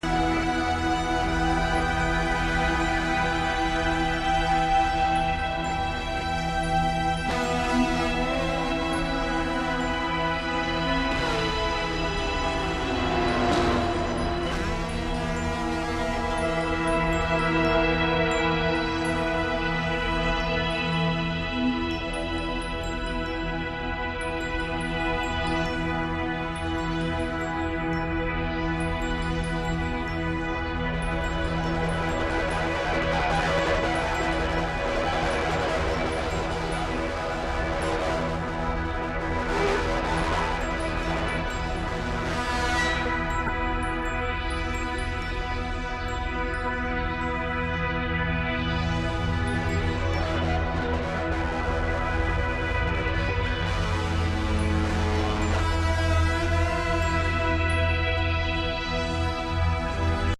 keyboards, “extended vocals” and Native American flutes
guitar